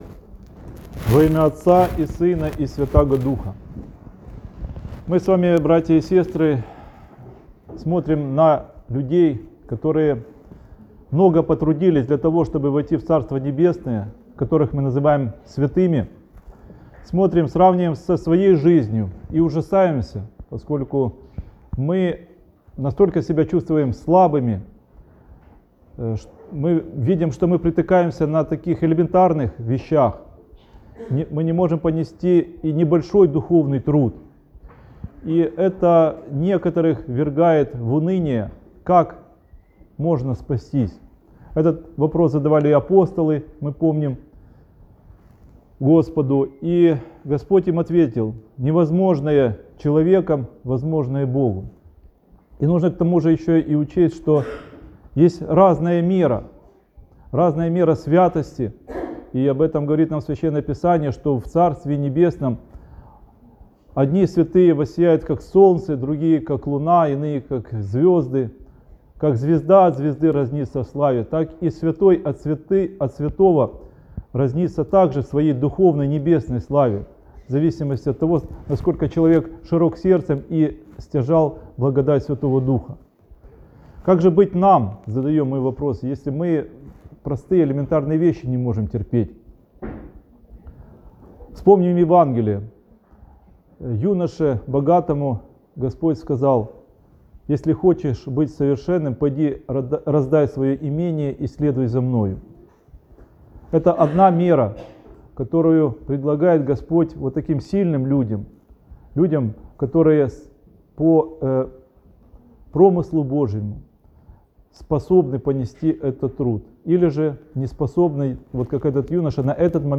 Прощёное воскресенье. Проповедь (АУДИО) | Макариевский храм, г. Киев